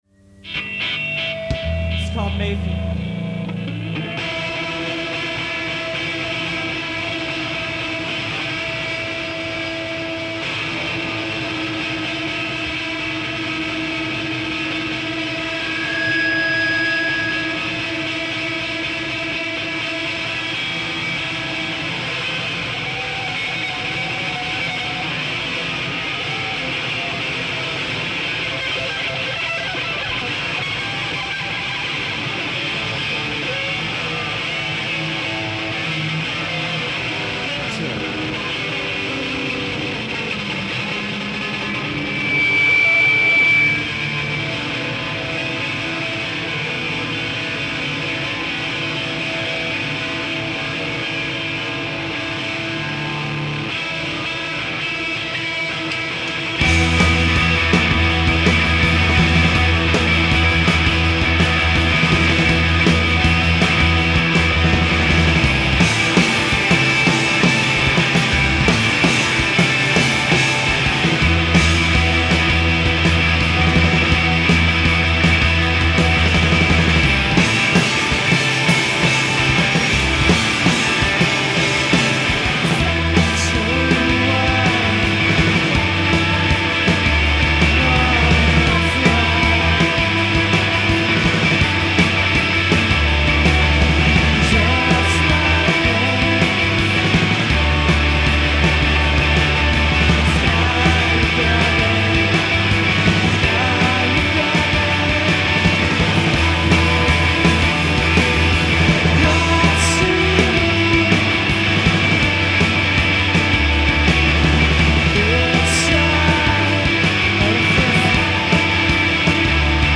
Vermonstress - Day 1